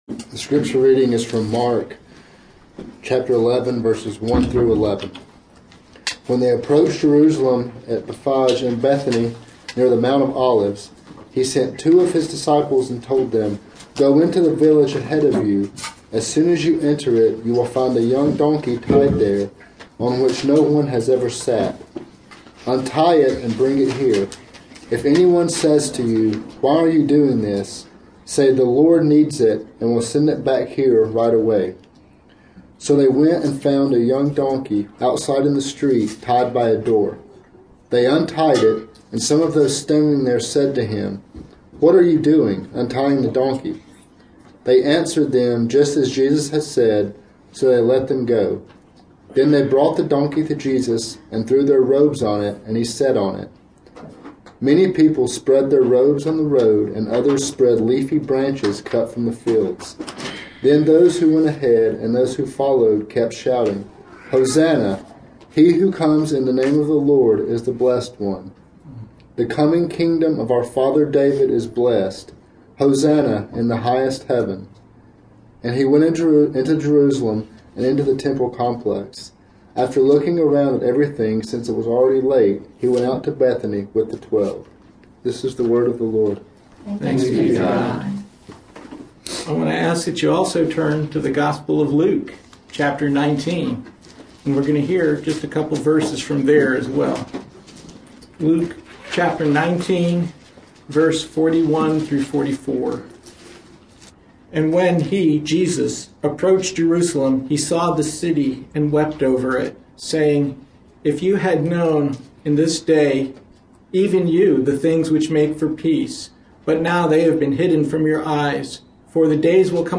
Passage: Mark 11:1-18 Service Type: Sunday Morning